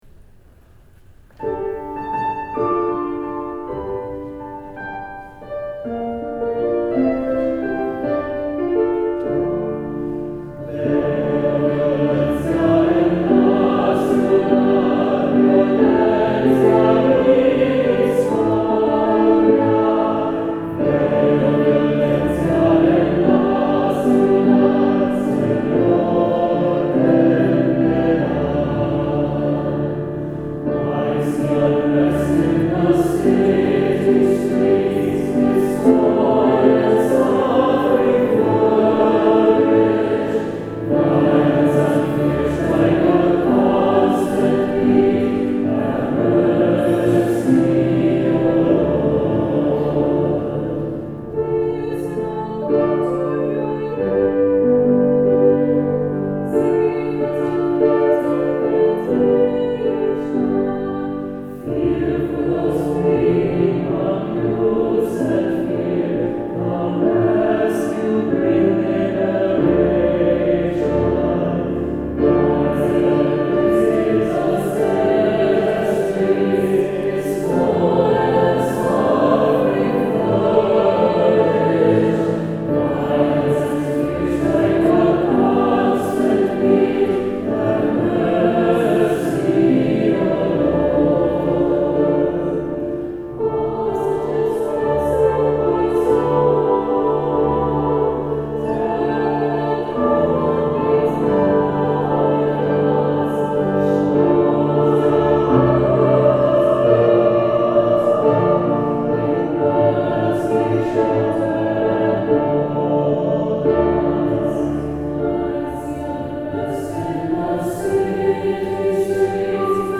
Voicing: SATB; Solo; Assembly